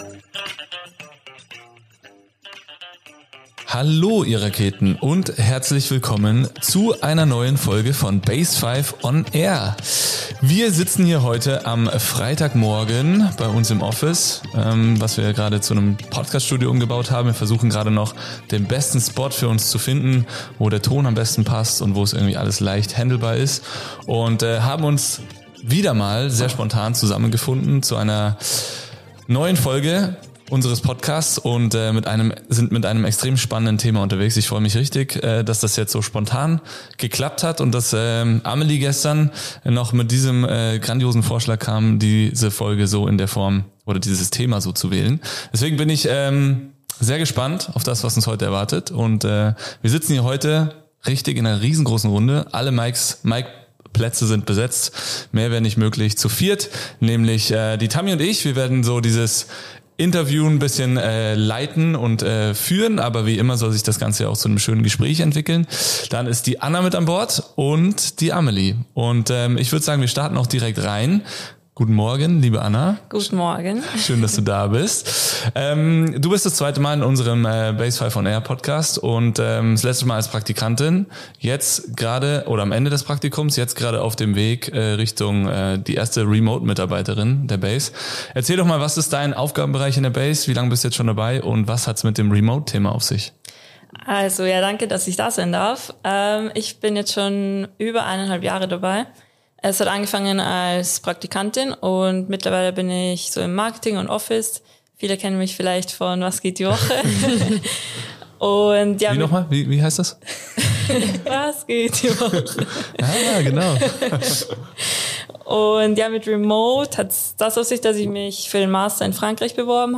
Ein Gespräch, das berührt – und Mut macht.